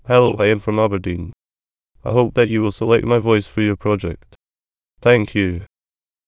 voices/VCTK_European_English_Males at main